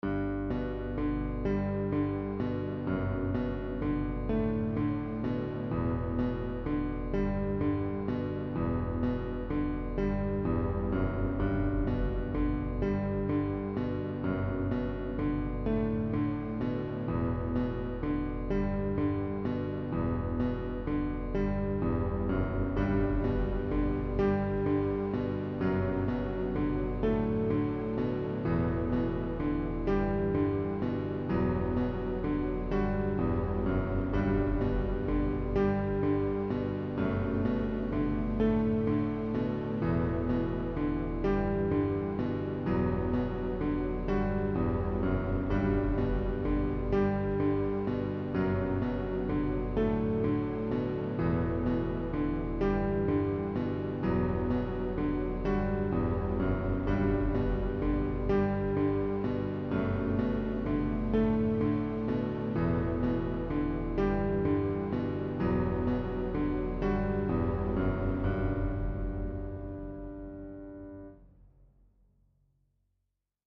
piano shite
not that great quality. not a full song in the group.